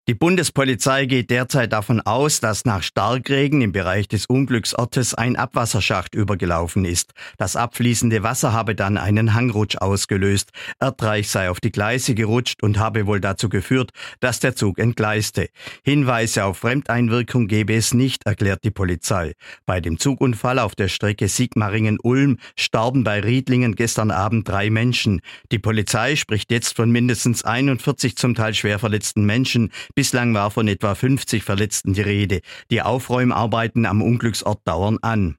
SWR-Reporter